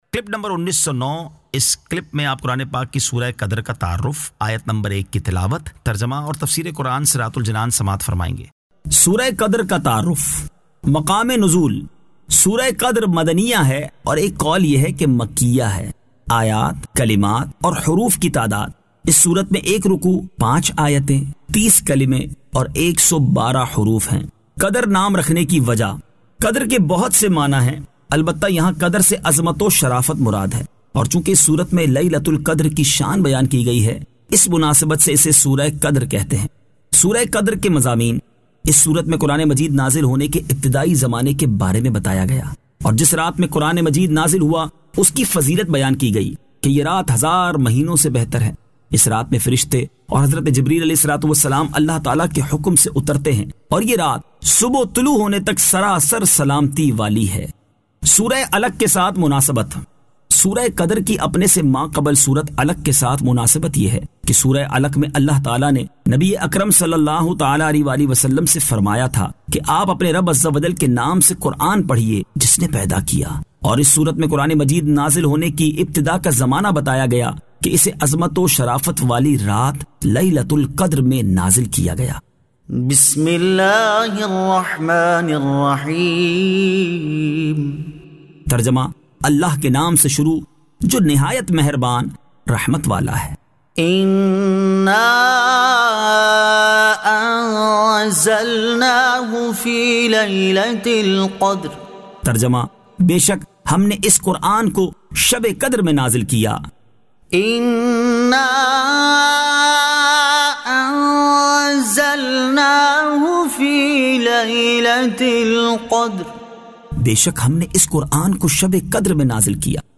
Surah Al-Qadr 01 To 01 Tilawat , Tarjama , Tafseer
2025 MP3 MP4 MP4 Share سُوَّرۃُ الْقَدْرْ آیت 01 تا 01 تلاوت ، ترجمہ ، تفسیر ۔